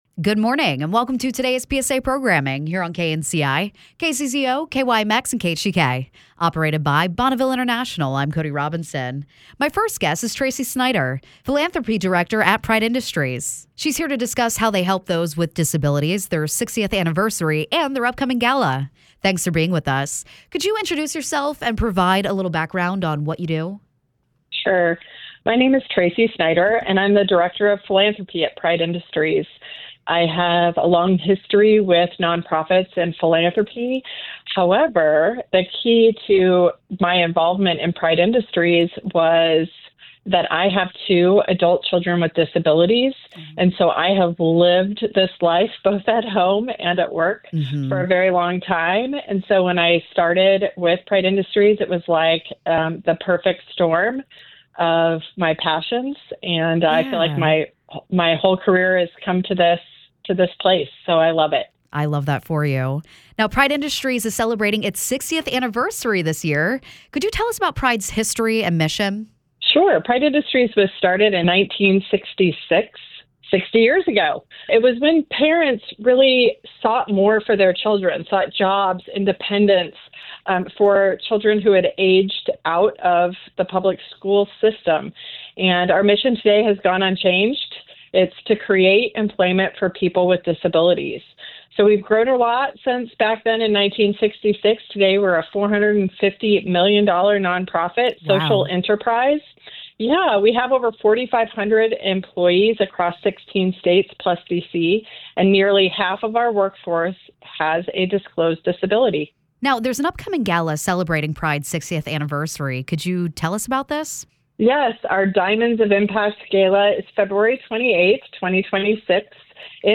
talks with host